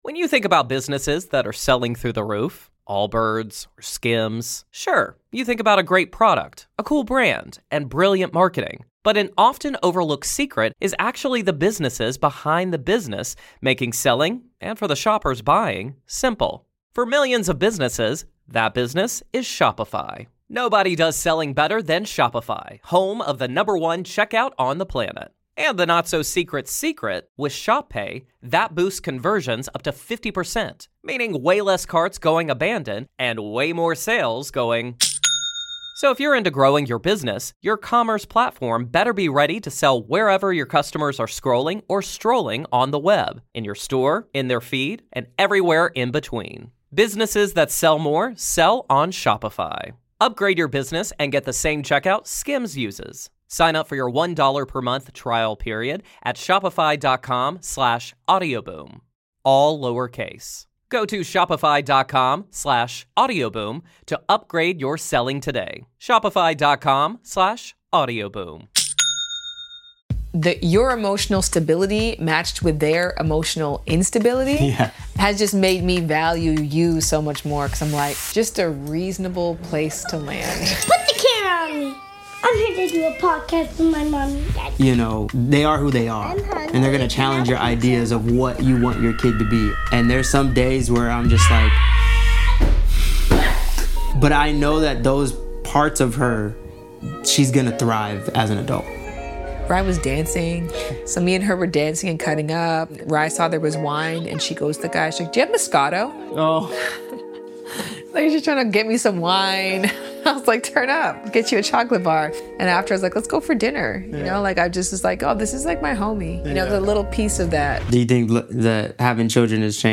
A conversation for anyone navigating love, partnership, and the wild joy of raising tiny humans.